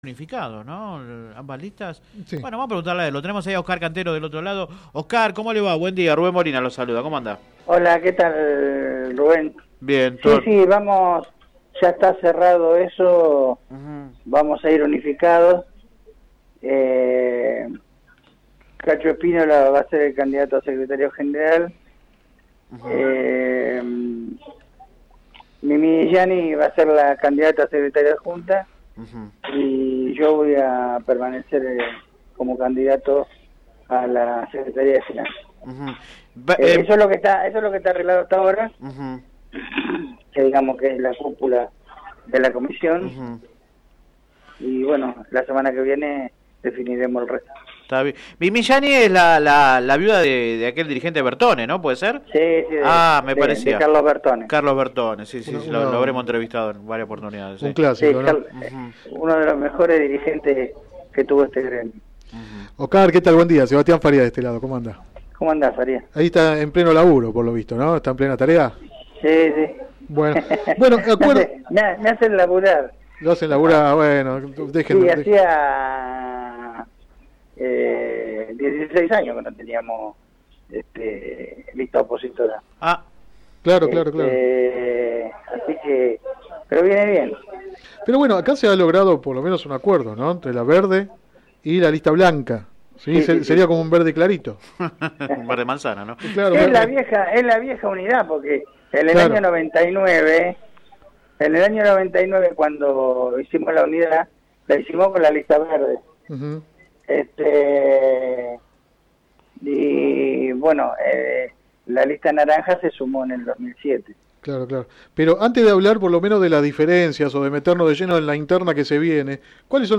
entrevista radial